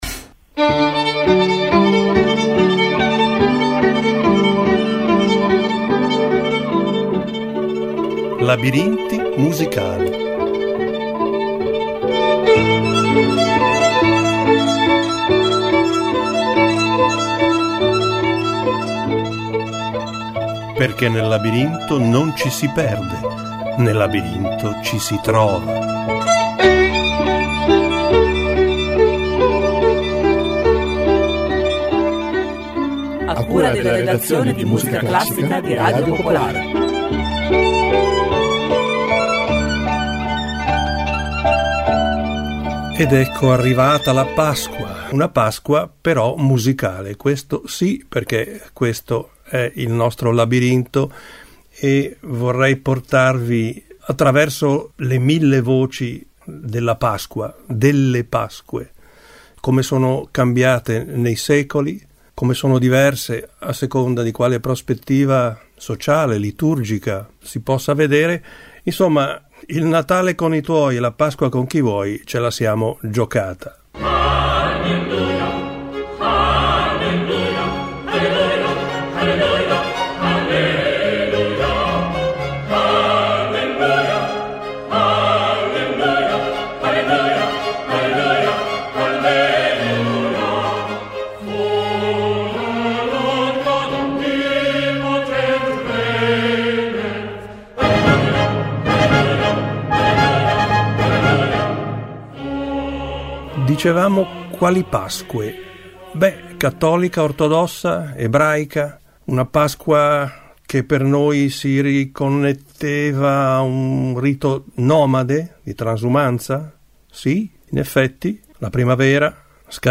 "Labirinti Musicali" ideato dalla redazione musicale classica di Radio Popolare, in ogni episodio esplora storie, aneddoti e curiosità legate alla musica attraverso racconti che intrecciano parole e ascolti.